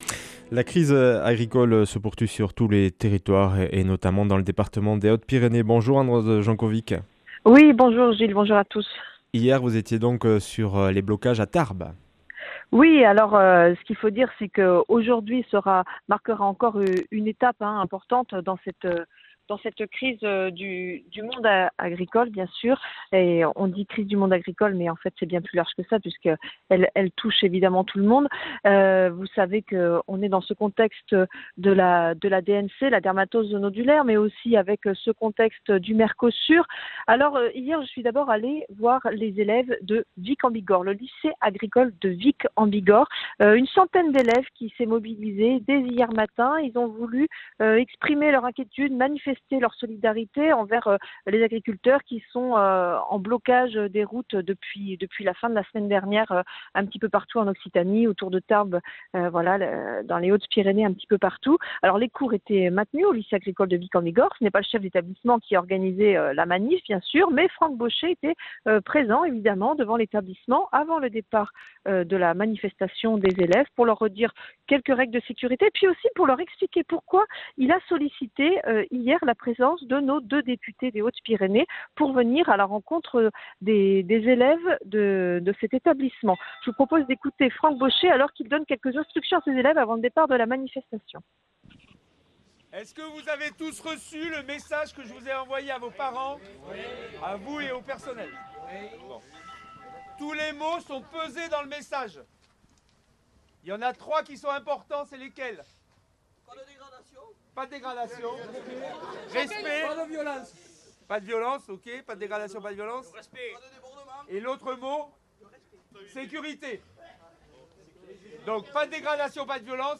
Sur le terrain dans les Hautes Pyrénées, avec les agriculteurs.
Accueil \ Emissions \ Information \ Locale \ Interview et reportage \ Sur le terrain dans les Hautes Pyrénées, avec les agriculteurs.